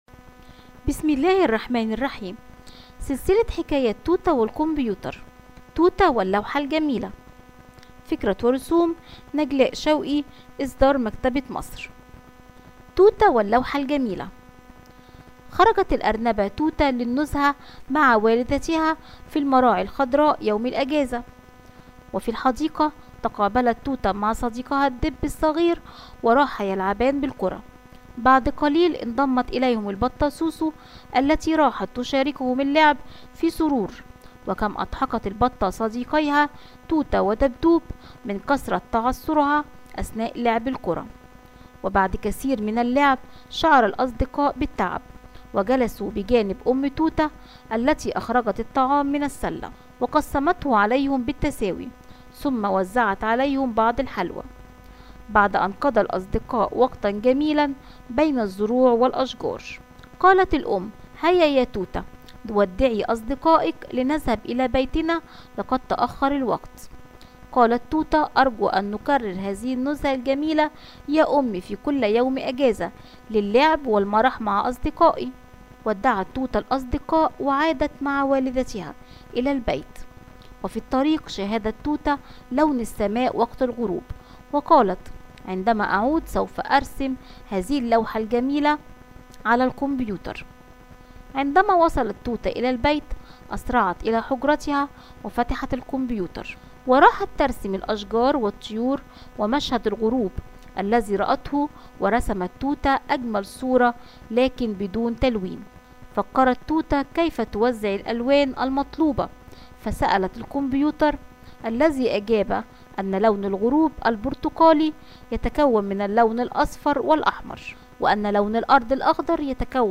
Tute ve Güzel Tablo – Arapça Sesli Hikayeler
Tute-ve-guzel-tablo-arapca-sesli-hikayeler.mp3